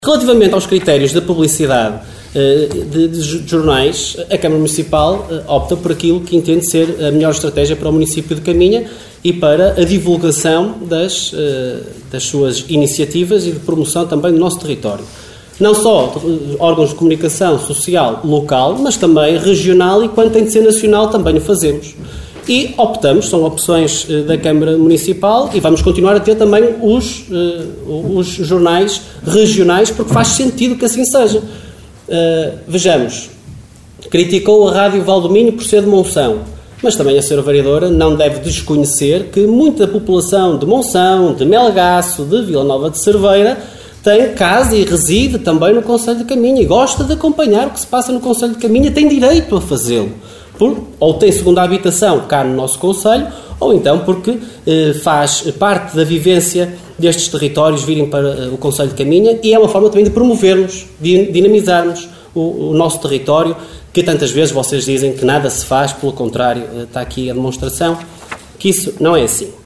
Excertos da última reunião de Câmara, realizada na passada quarta-feira no Salão Nobre dos Paços do Concelho, para contratação de 10 funcionários e atribuição de subsídios.